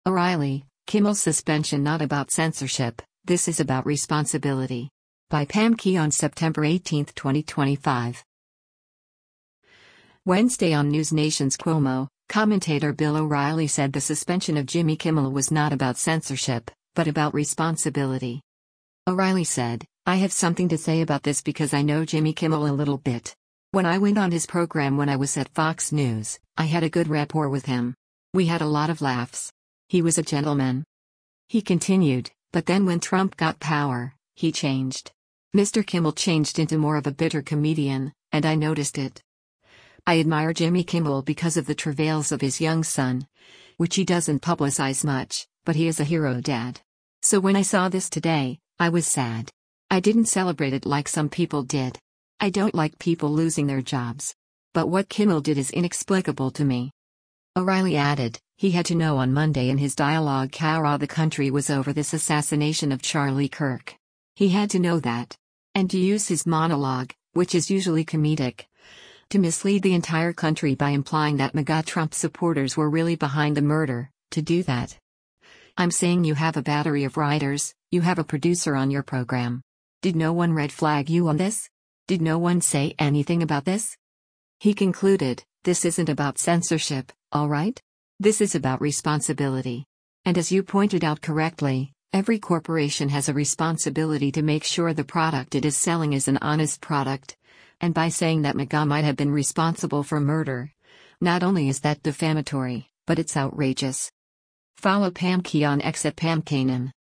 Wednesday on NewsNation’s “Cuomo,” commentator Bill O’Reilly said the suspension of  Jimmy Kimmel was not about “censorship,” but about “responsibility.”